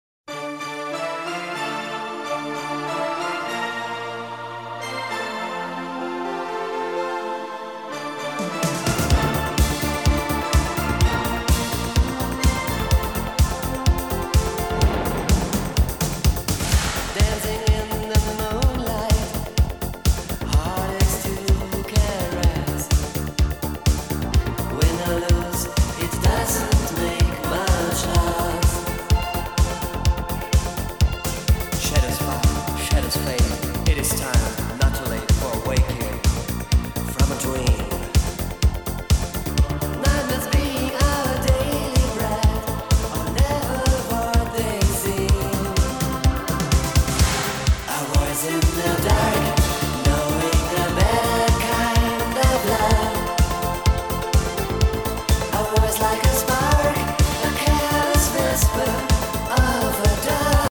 Барабаны евро/диско 80-90х. подскажите?!